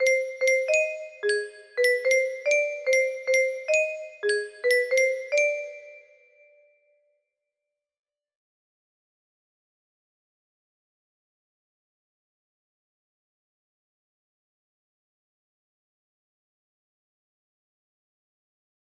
Starting music - melody music box melody
Yay! It looks like this melody can be played offline on a 30 note paper strip music box!